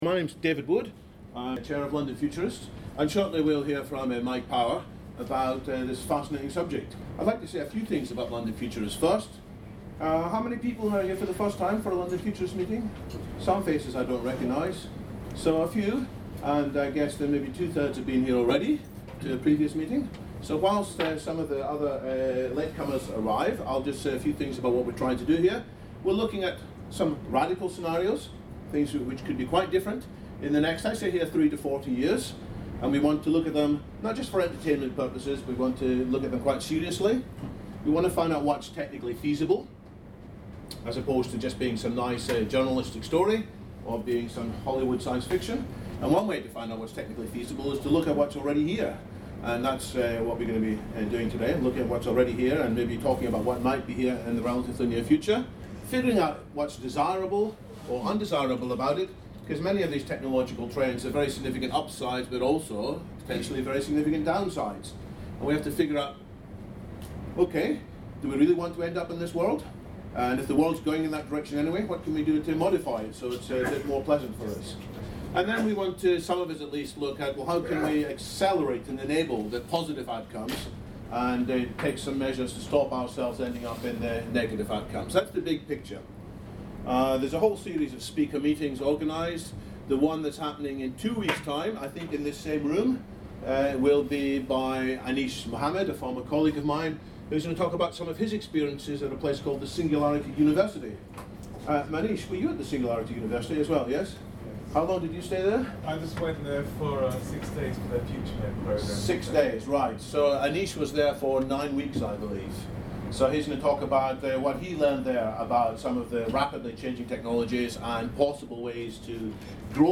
On this occasion, the recording excludes the audience Q&A from the final portion of the meeting.